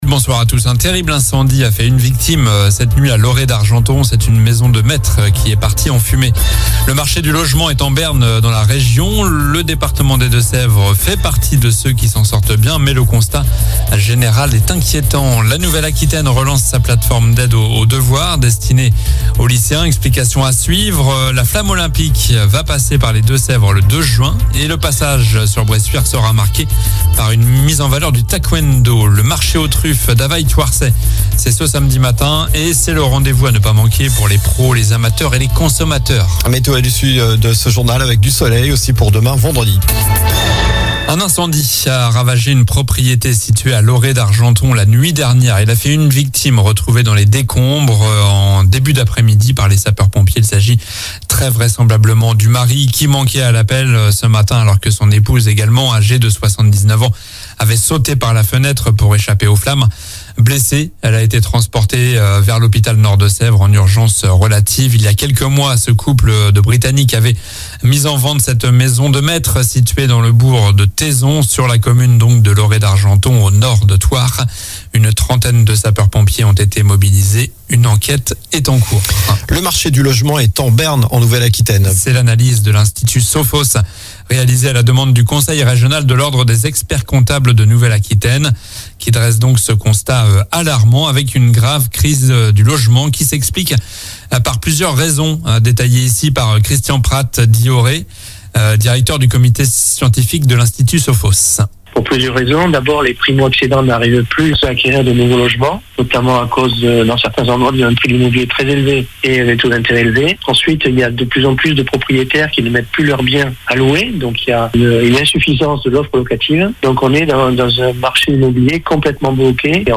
Journal du jeudi 11 janvier (soir)